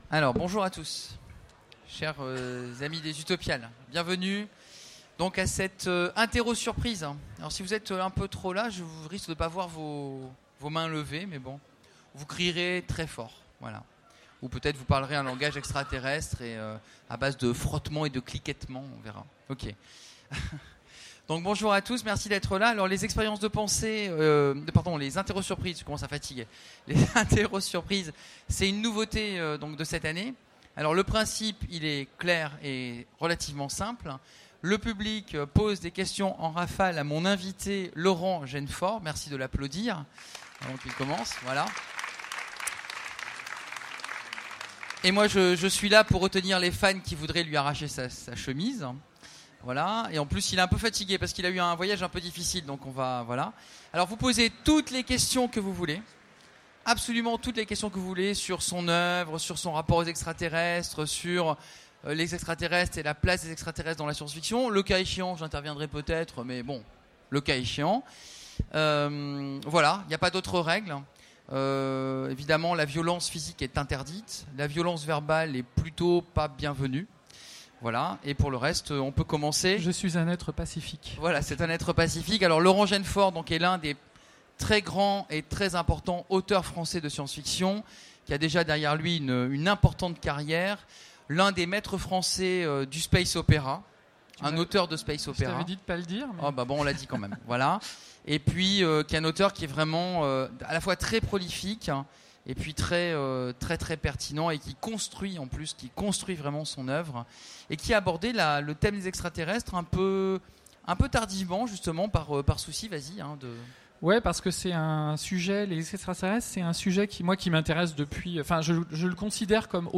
Mots-clés Extraterrestre Conférence Partager cet article